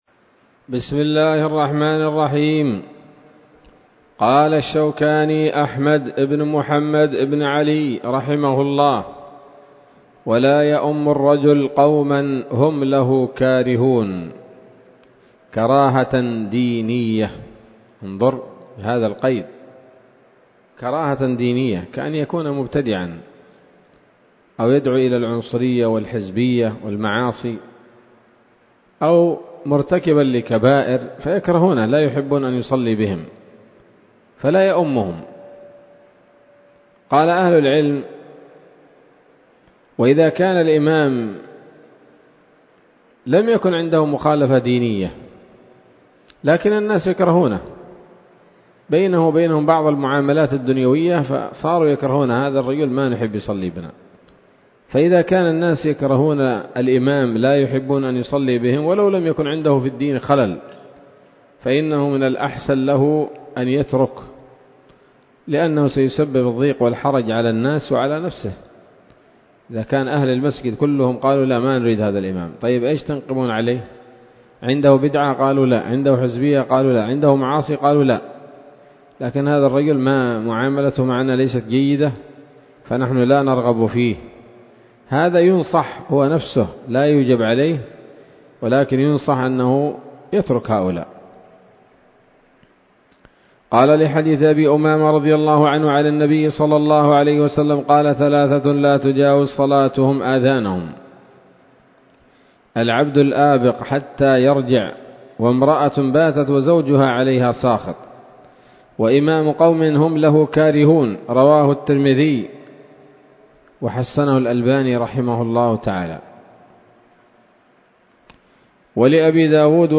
الدرس السابع والعشرون من كتاب الصلاة من السموط الذهبية الحاوية للدرر البهية